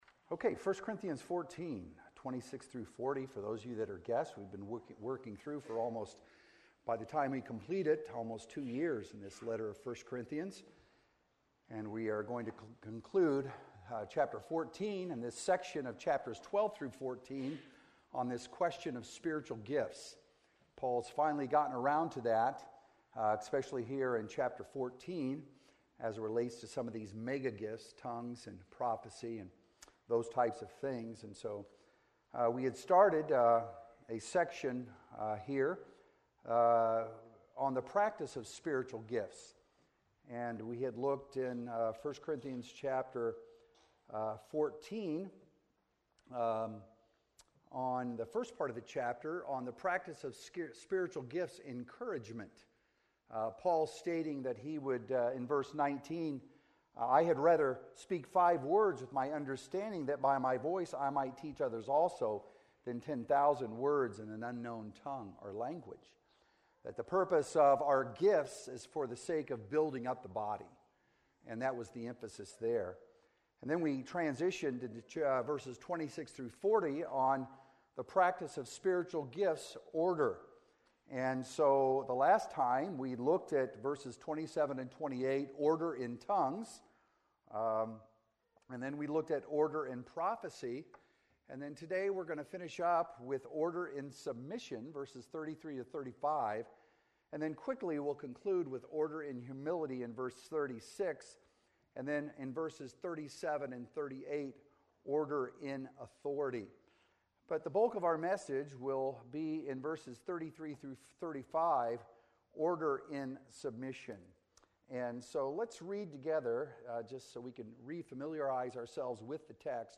Sermons - Emmanuel Baptist Church
From Series: "Sunday Mornings"